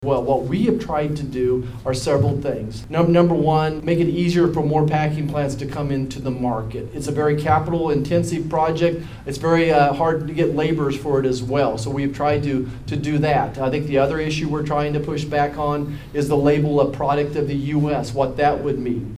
Senator Roger Marshall spoke to members of the community during his town hall over the weekend.